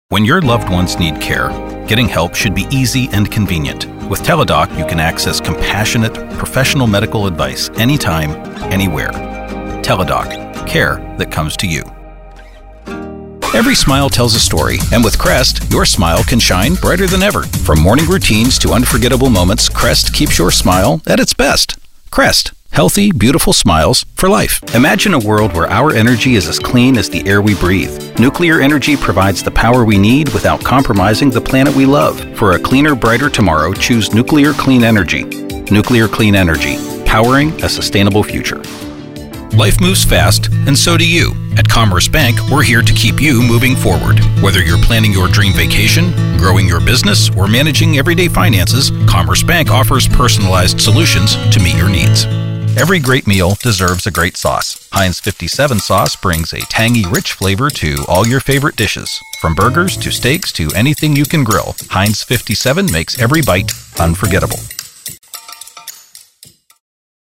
Male
English (North American)
Adult (30-50), Older Sound (50+)
Radio Commercials